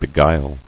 [be·guile]